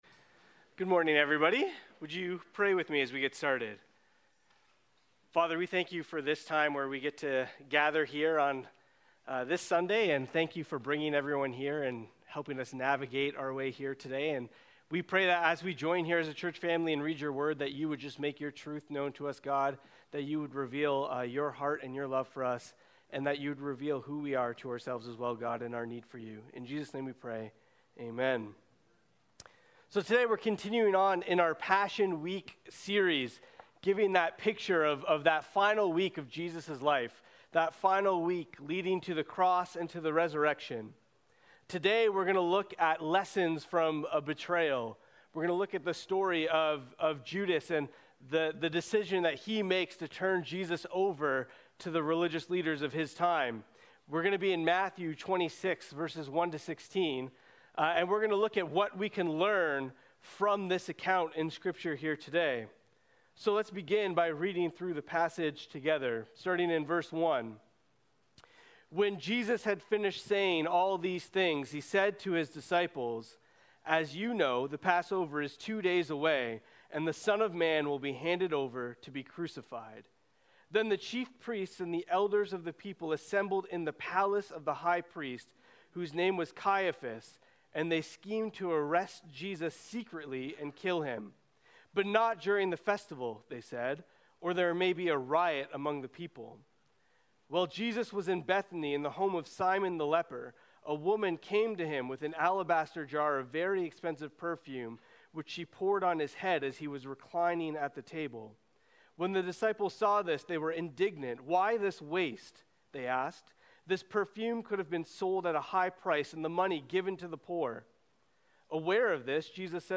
Sermons | James North Baptist Church